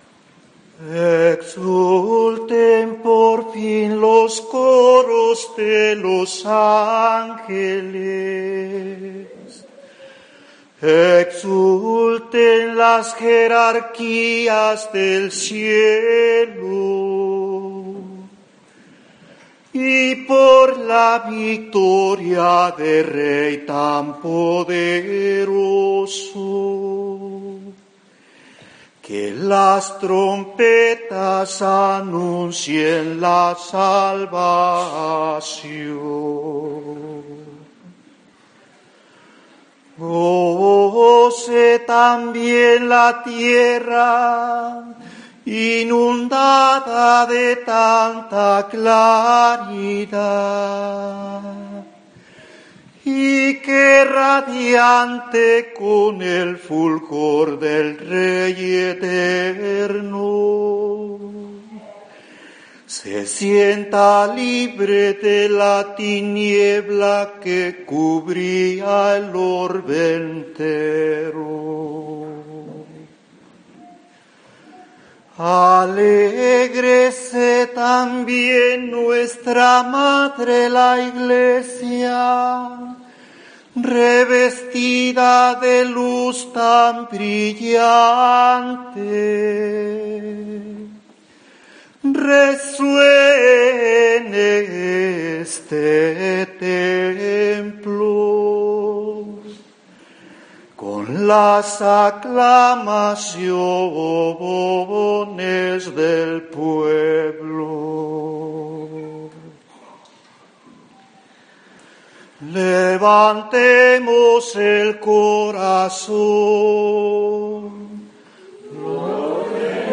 Exsultet Pregón Pascual 2017 Sagrado Corazón Austin
Cantos